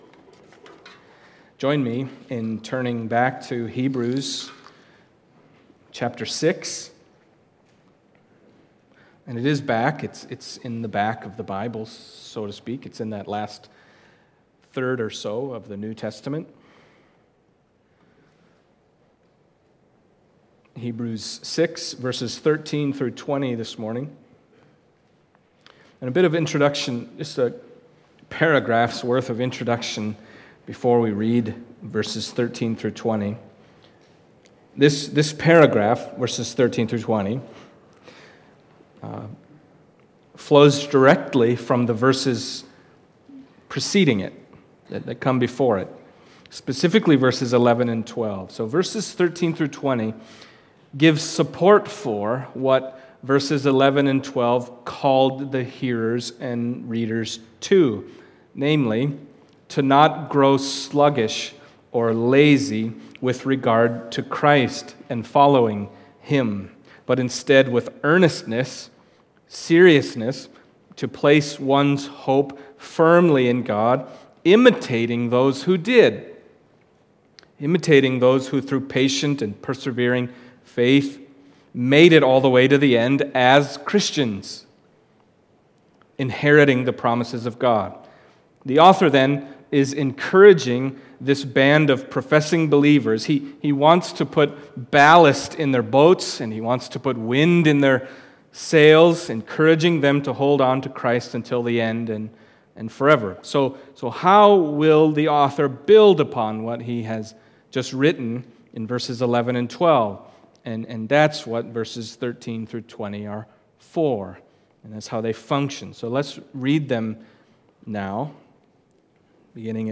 Hebrews Passage: Hebrews 6:13-20 Service Type: Sunday Morning Hebrews 6:13-20 « The Full Assurance of Hope to the End What’s With Melchizedek?